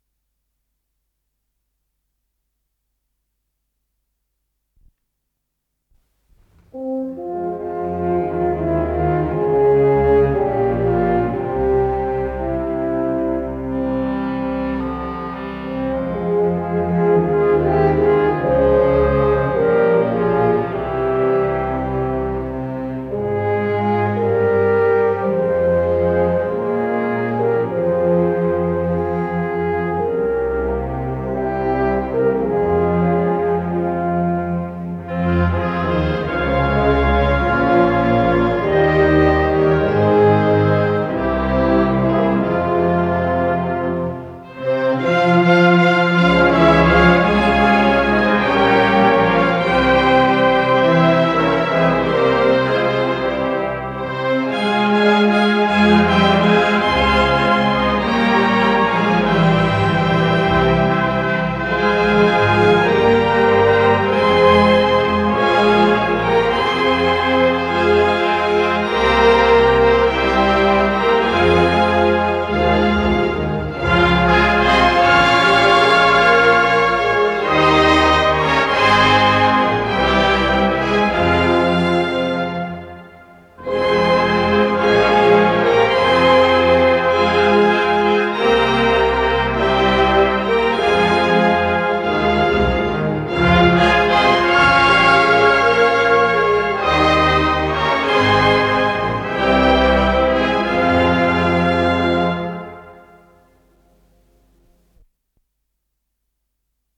с профессиональной магнитной ленты
ИсполнителиСимофнический оркестр